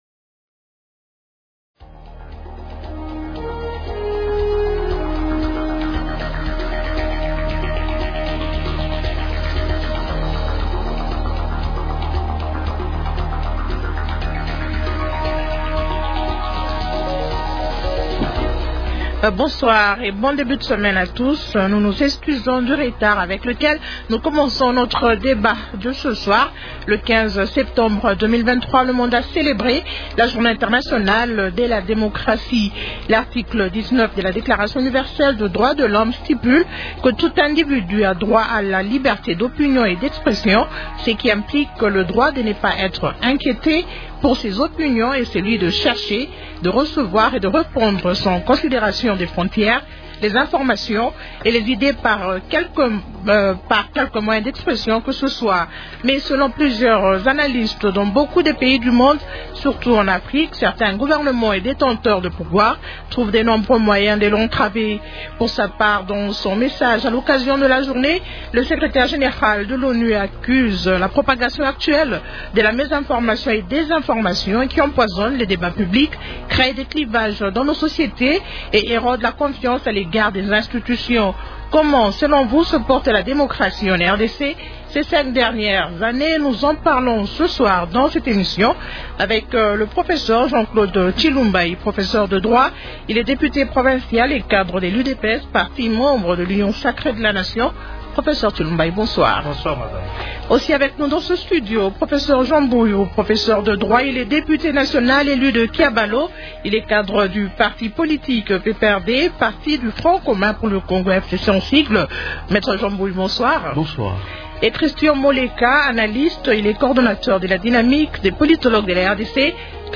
L'actualité politique de ce soir